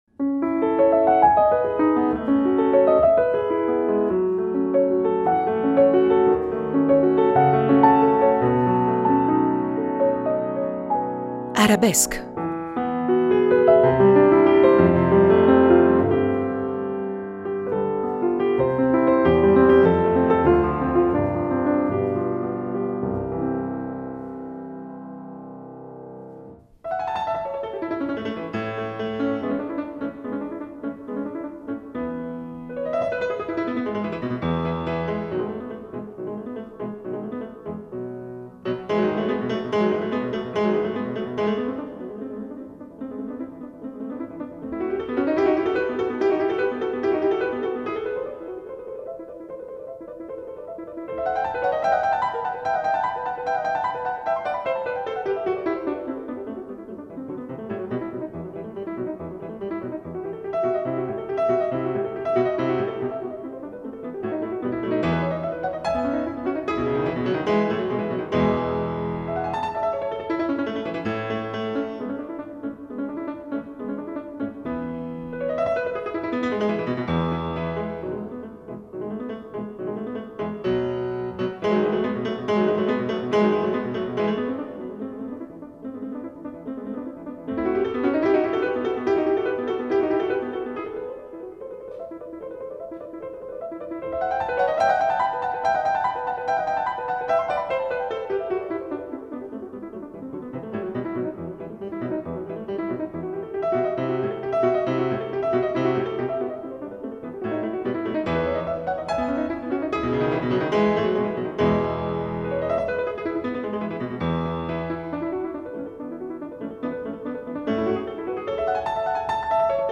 Al pubblico presente a questo evento veniva data la possibilità di incontrare e conoscere la celebre pianista in una conversazione estemporanea